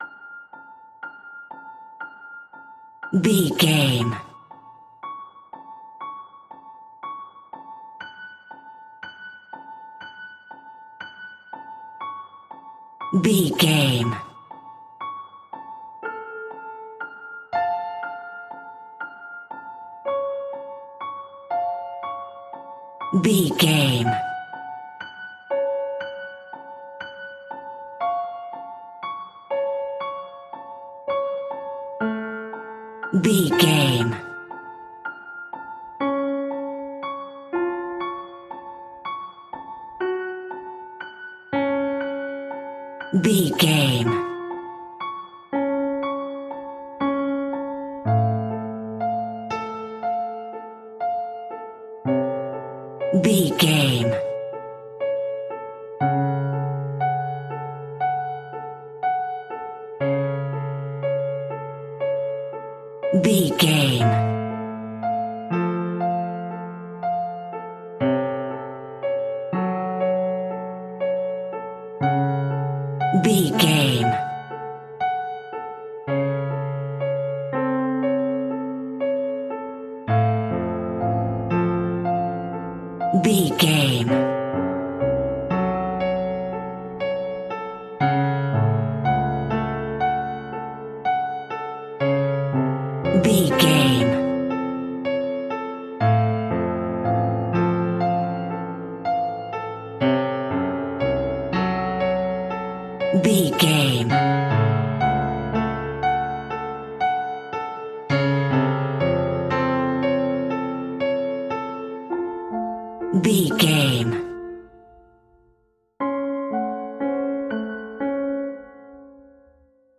Haunted Piano Music Notes.
Aeolian/Minor
ominous
dark
haunting
eerie
Acoustic Piano